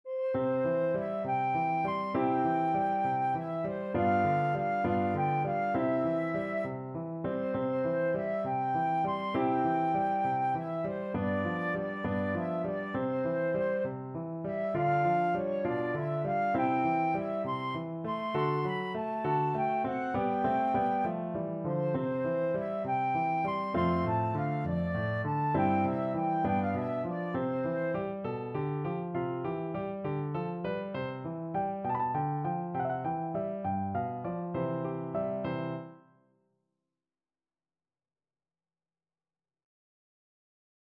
Flute
C major (Sounding Pitch) (View more C major Music for Flute )
6/8 (View more 6/8 Music)
~ = 100 Fršhlich
Classical (View more Classical Flute Music)